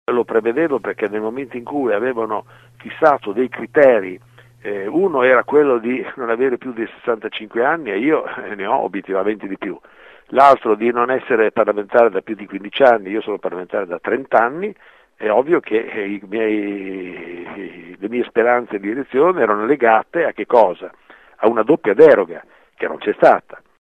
Ascolta Filippo Berselli